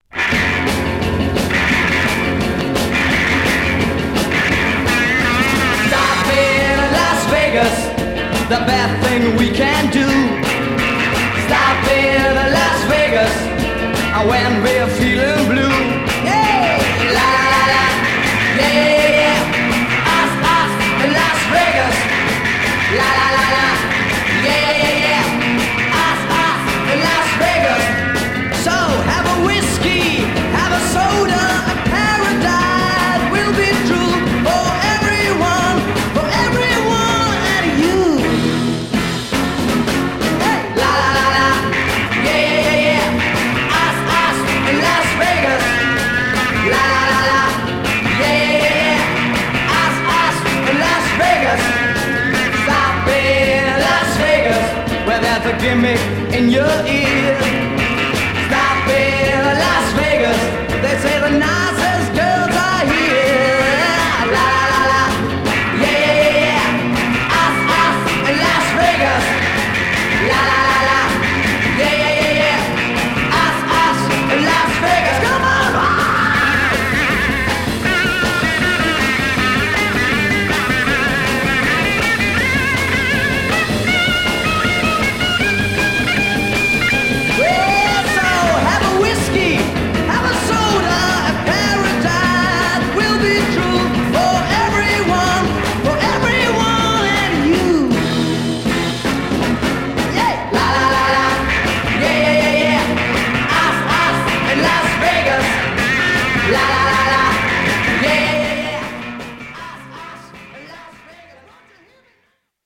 German Garage freakbeat